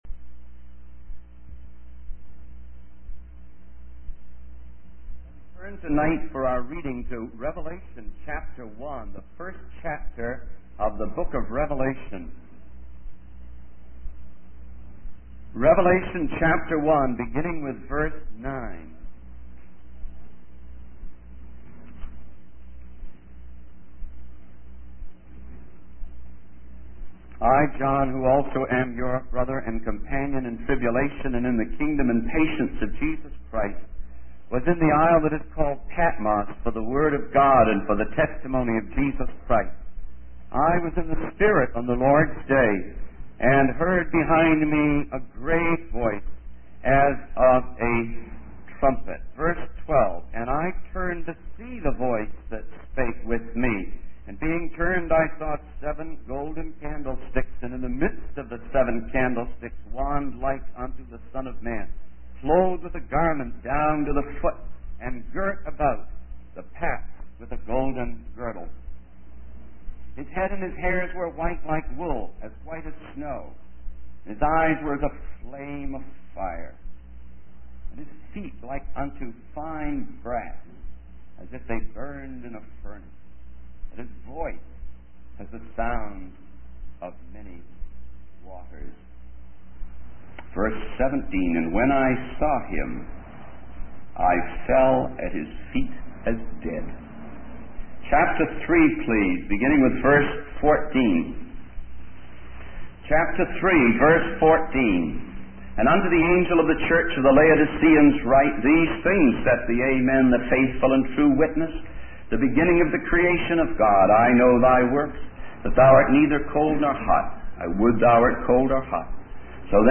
In this sermon, the speaker shares a personal experience where he was confronted by the Lord about his happiness and the material possessions in his life. The speaker realizes that material things do not bring true satisfaction and that his life lacks power.